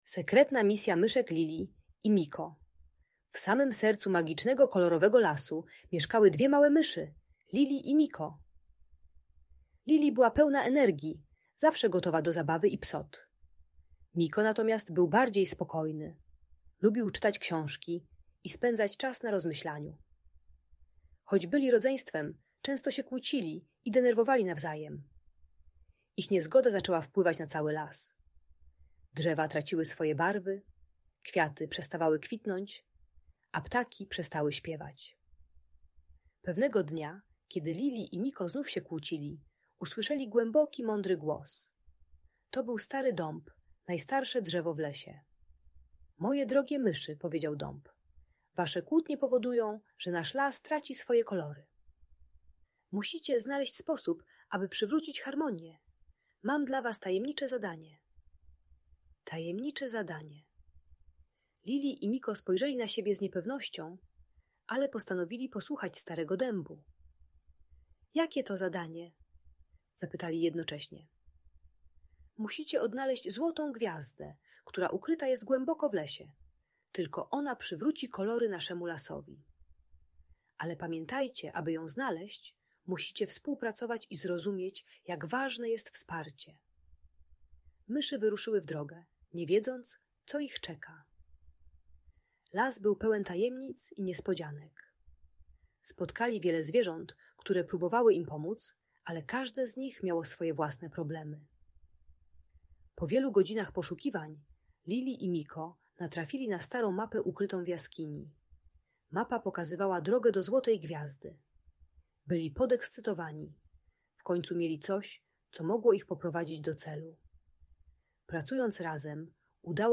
Sekretna Misja Myszek Lili i Miko - Audiobajka